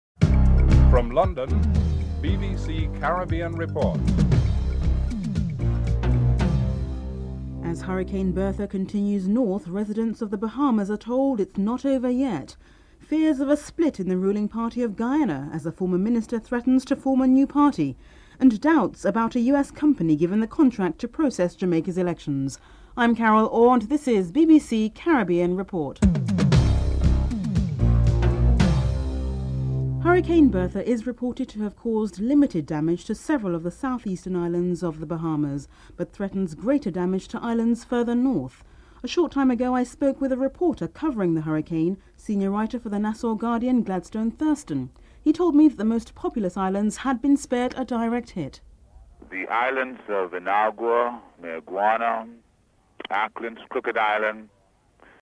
1. Headlines (00:00-00:29)
3. The US has announced the first action against a company trading with Cuba under its new law which tightened sanctions on the island. State Department spokesman Nicholas Burns is interviewed (03:38-04:49)
Caricom Chairman Lester Bird and Prime Minister Denzil Douglas are interviewed (06:49-09:15)